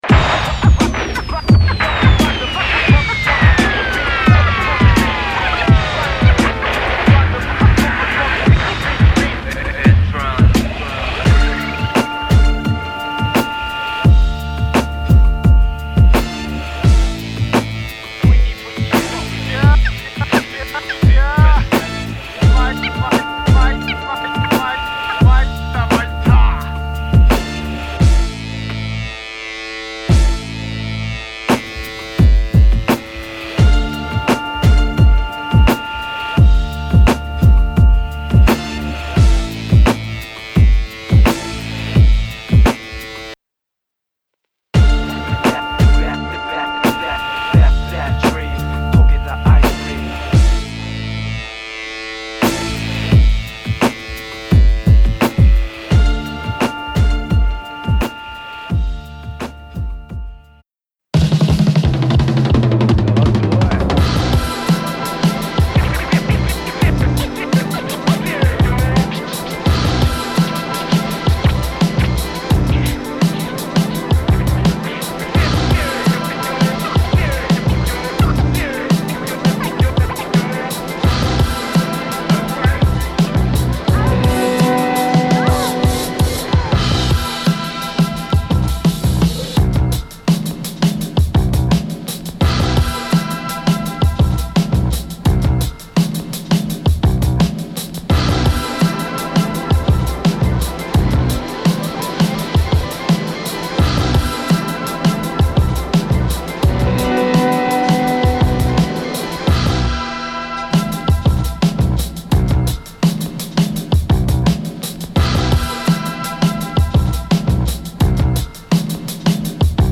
その15tracksは浄化作用が働くようなストレートなアウトサイド、後効きスタイル。